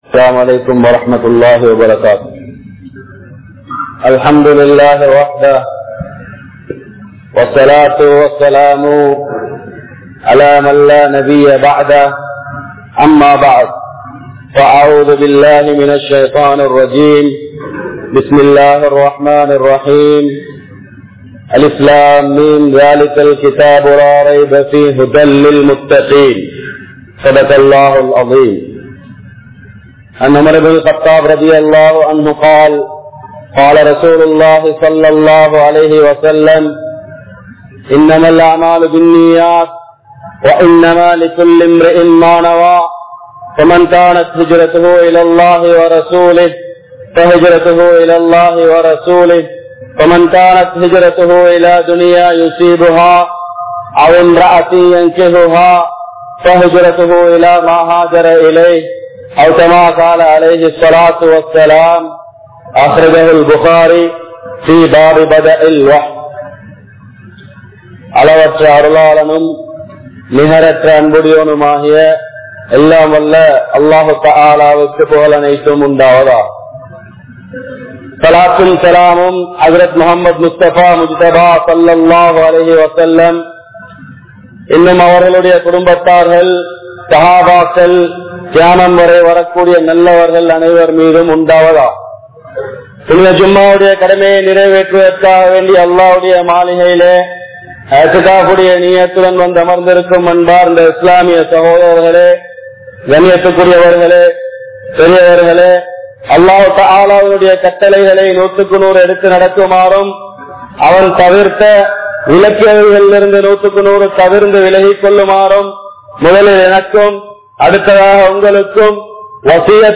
BoathaiVasthum Intraya Vaalifarhalum | Audio Bayans | All Ceylon Muslim Youth Community | Addalaichenai